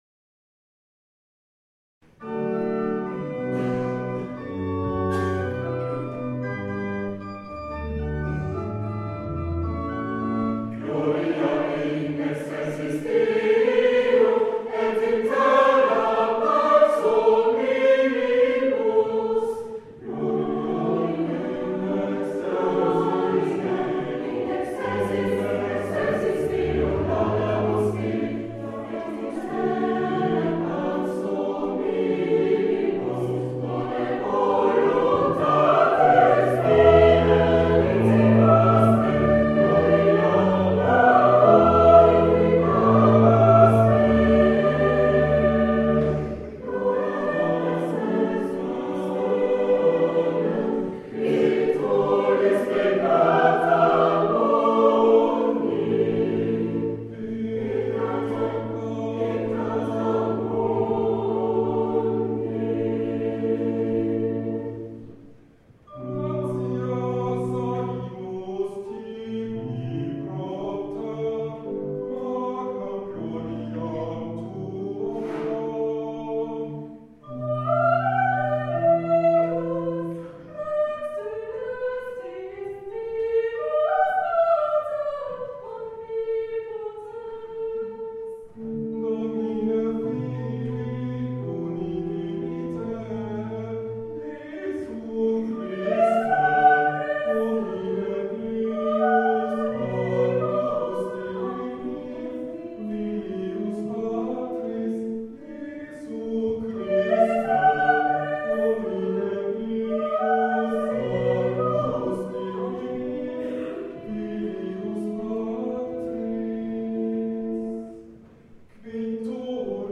Ostersonntag 2011
Wir singen mit Orgelbegleitung die Missa Lumen von Lorenz Mayerhofer und traditionelle Osterlieder - Originalmitschnitt